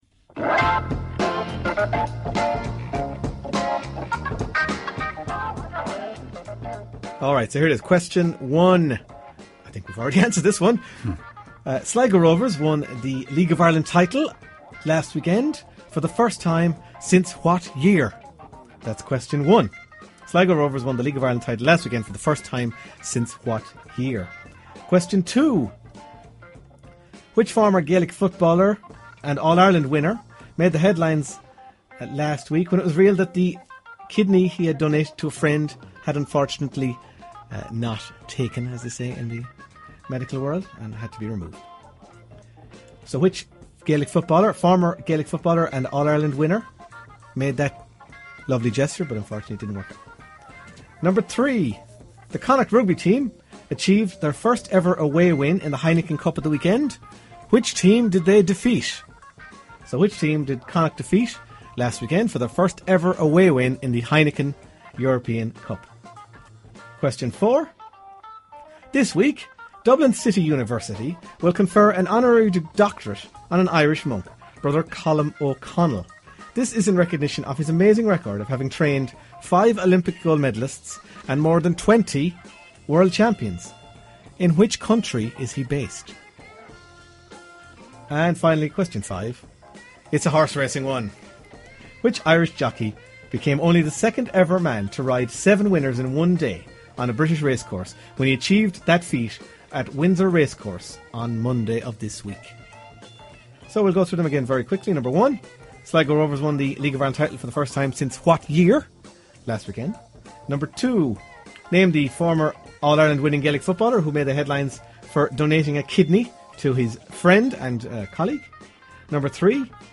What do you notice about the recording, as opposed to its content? Five sports trivia questions from the Half-time Team Talk show on Claremorris Community Radio.